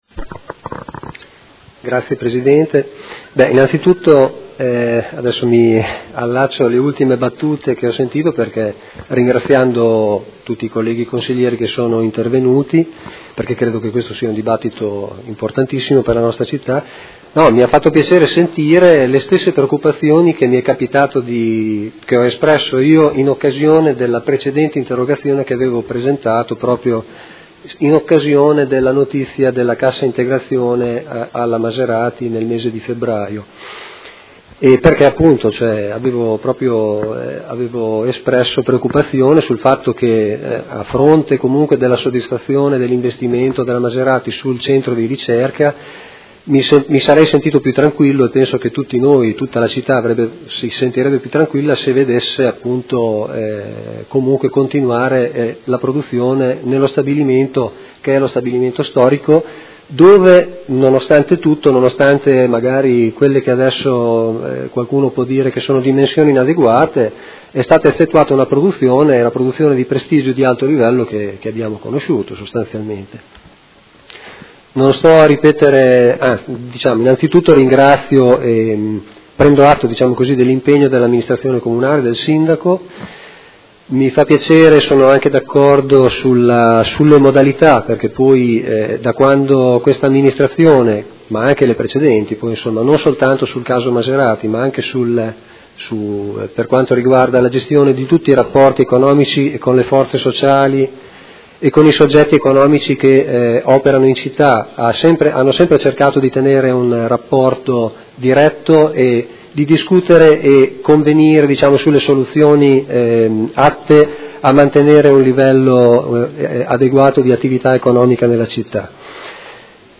Seduta del 20/04/2016. Dibattito su interrogazione dei Consiglieri Malferrari, Trande e Bortolamasi (P.D.) avente per oggetto: Si aggrava la crisi alla Maserati di Modena
Audio Consiglio Comunale